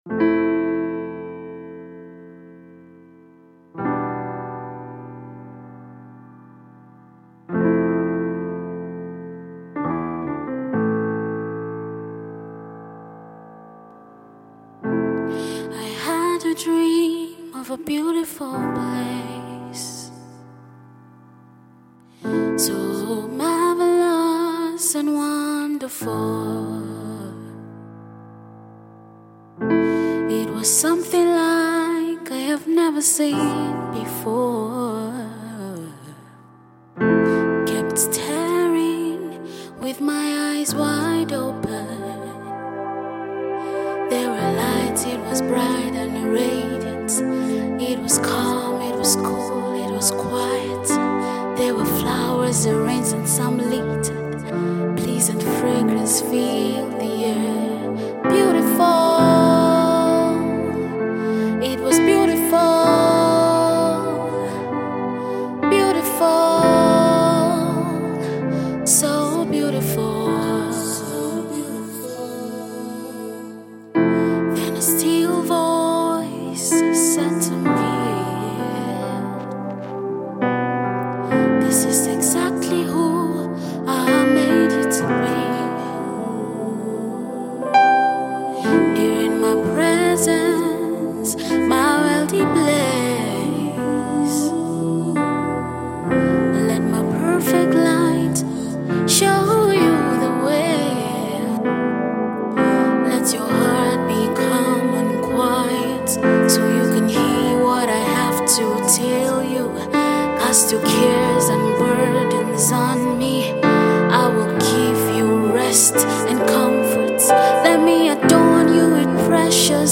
Wow! Such an angelic voice.
Hmmmm… Angelic voice and a beautiful song